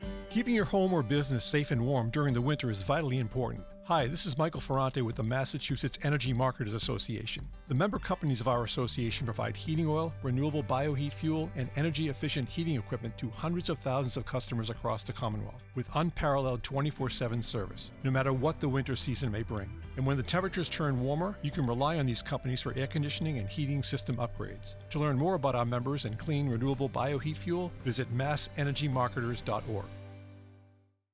• January 2026 Heating Oil Industry Radio: 30 Second Spot